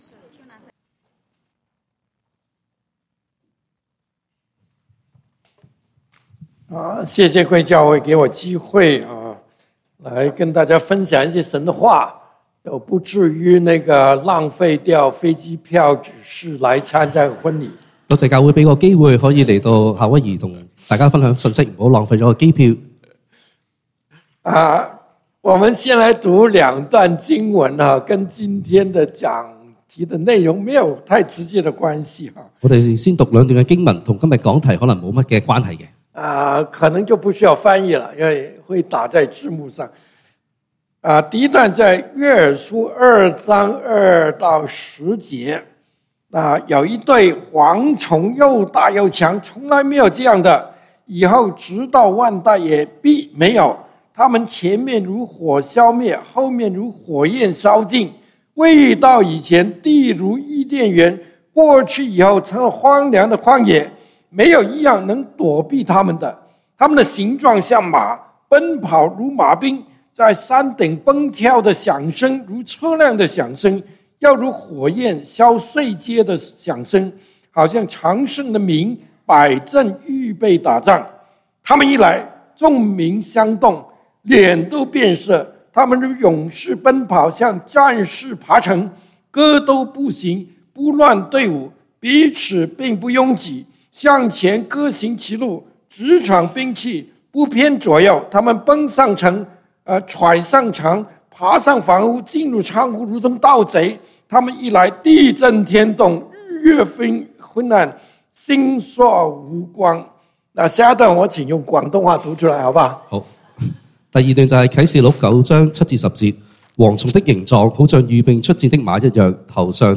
11/10 第二堂崇拜：包羅萬有的耶穌 (經文：希伯來書 9:24-28) | External Website | External Website